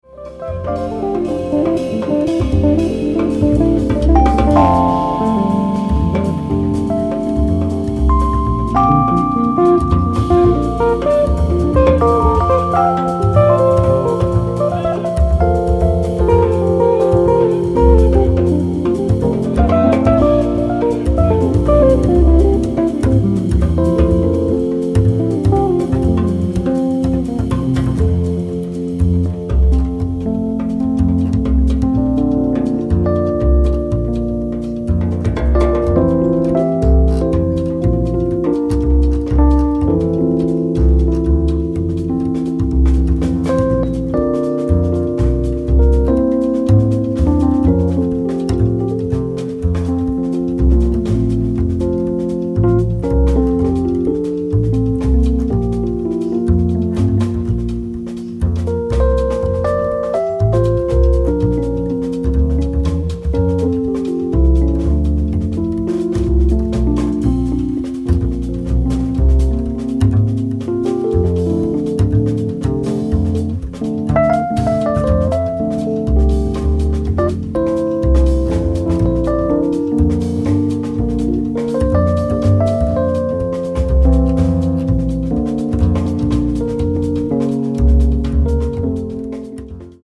guitar
Fender Rhodes
bass
drums